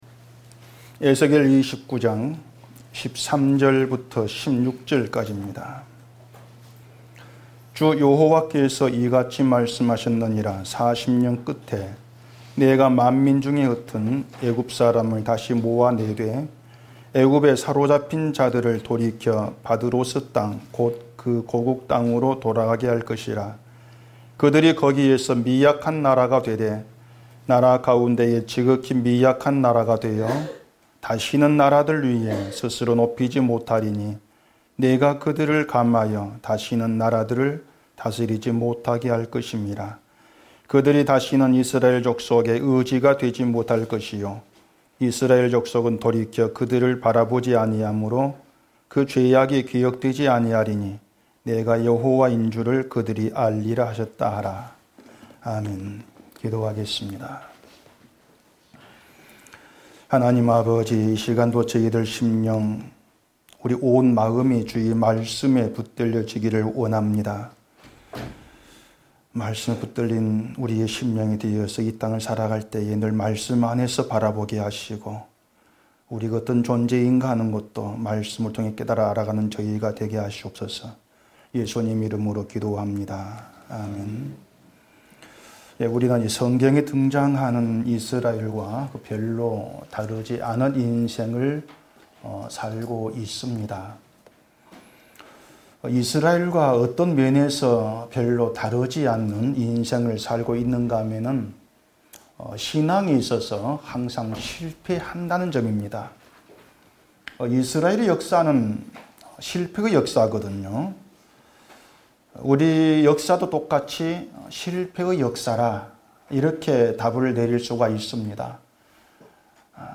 <설교>